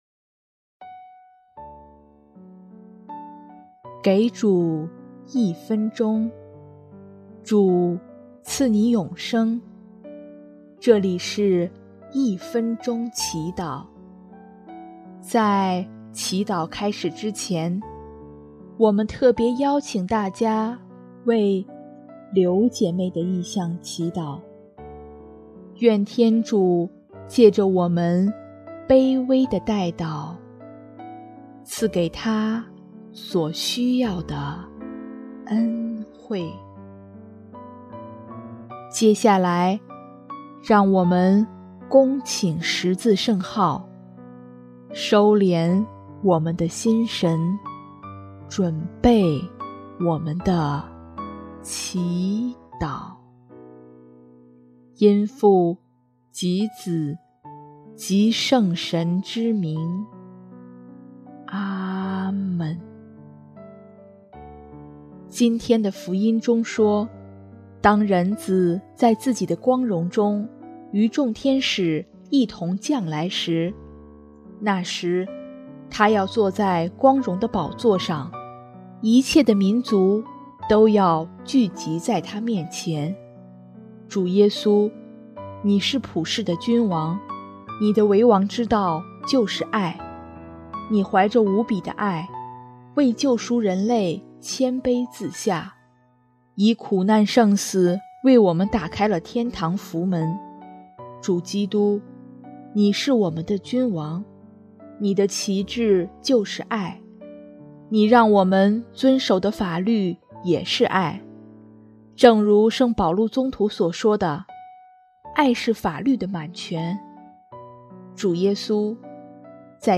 音乐：主日赞歌